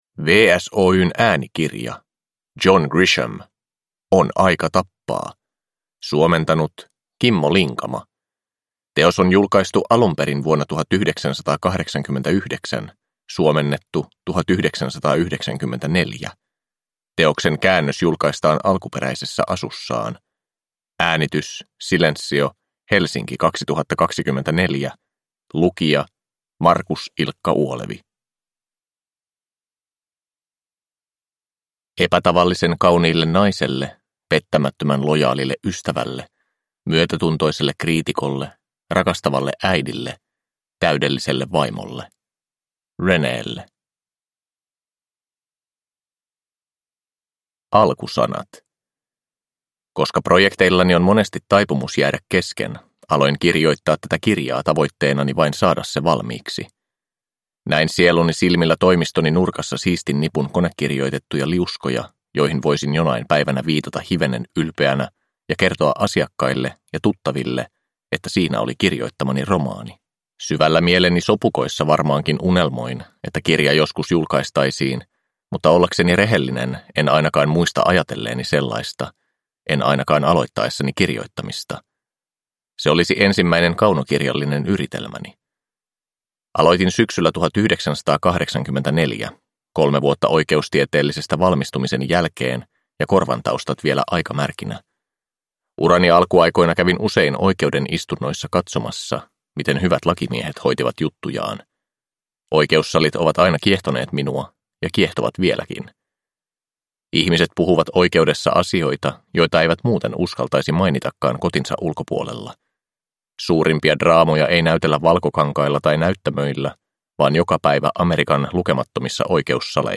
On aika tappaa – Ljudbok